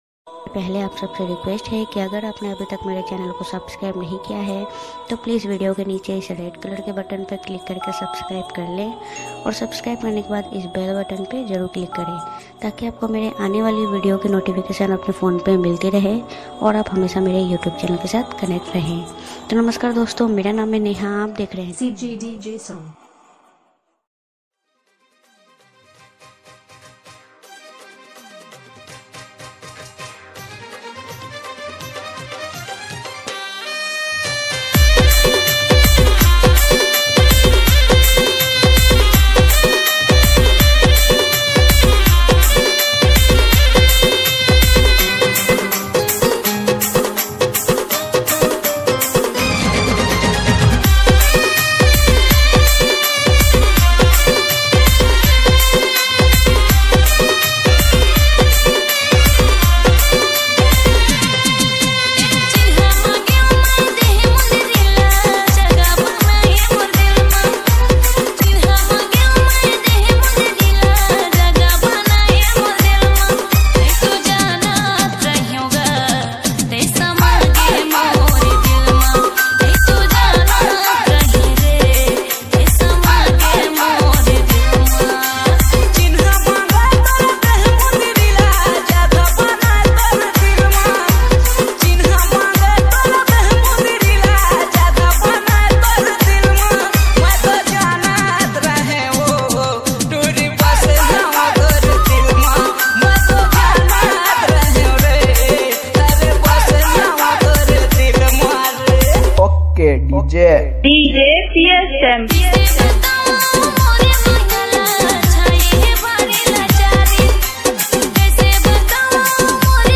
CG ROMANTIC DJ REMIX